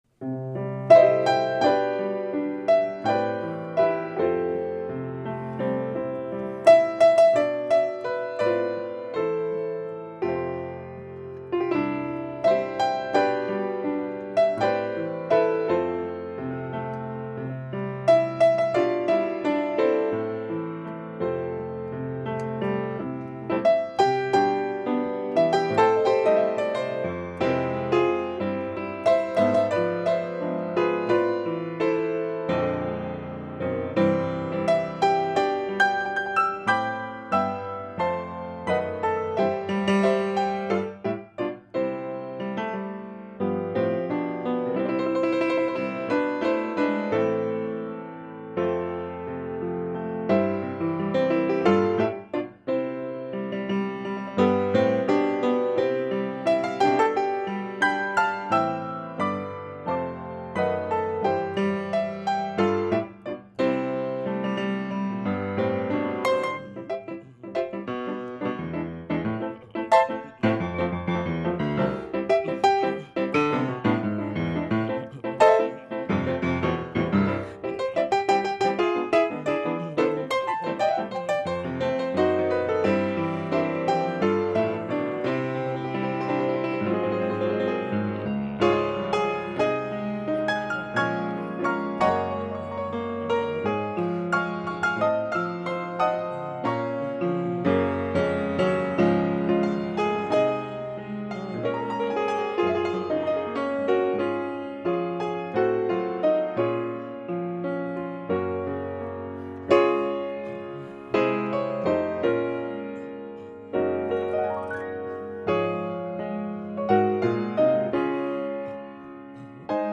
In a session with Jazz24, he even yodels when he talks about cowboy songs.
Pianist Monty Alexander embraces a wide variety of musical styles.